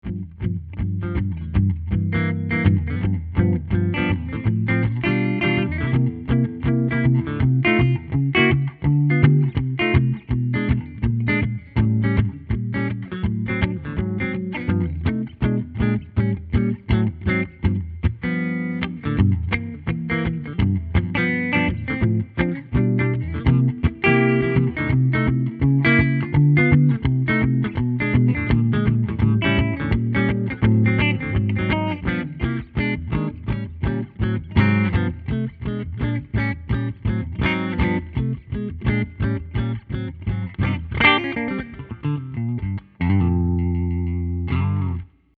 Here are 12 quick, 1-take MP3 sound files of myself playing this guitar, to give you an idea of what to expect. The guitar has great tone, sustain, and body, and is also capable of some nice controlled feedback effects. These tracks are all recorded using a Peavey Studio Pro 112 amp with a a Sennheiser MD441 mic, recorded straight into a Sony PCM D1 flash recorder, and MP3s were made in Logic, with no EQ or effects.
9 | Jazz
(Jazz Standard in G)